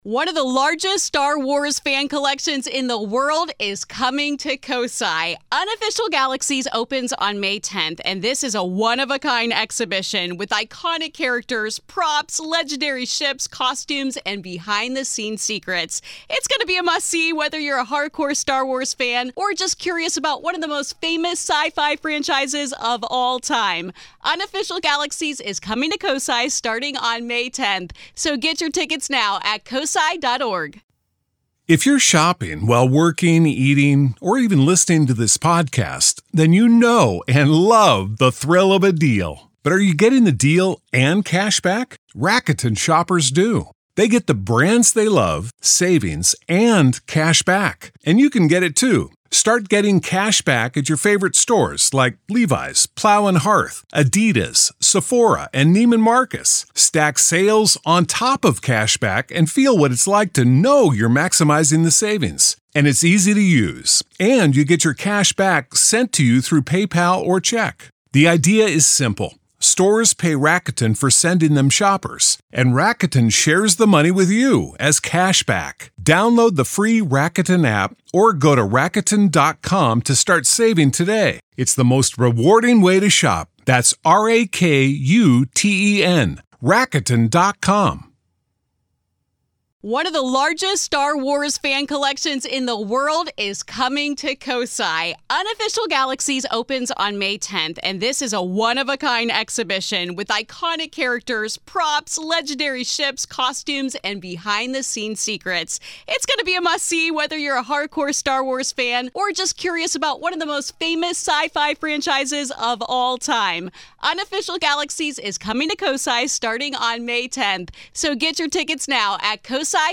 Listen as she recounts the horrifying moments that led her to trust her instincts and walk away from a house that may have been more than it seemed.
Grave Confessions is an extra daily dose of true paranormal ghost stories told by the people who survived them!